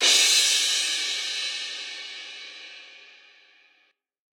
cym C.ogg